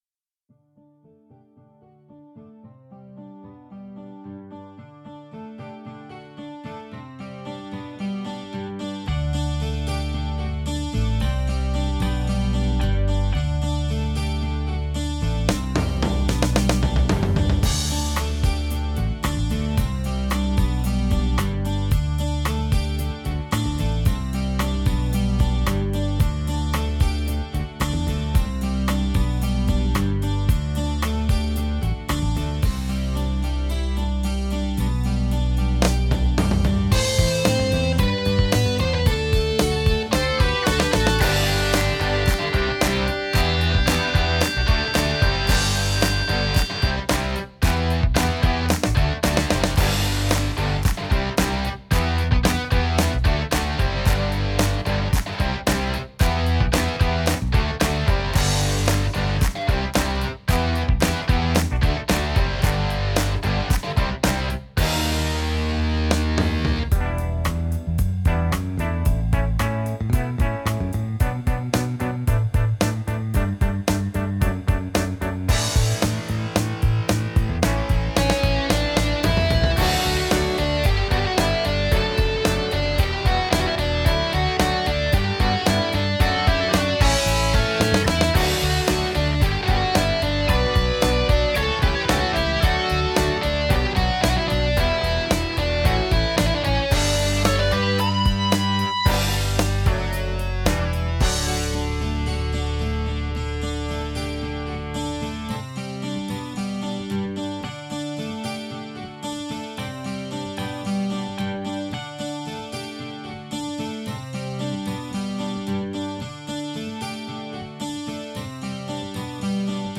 Filed under: Cover, MIDI Music, Remix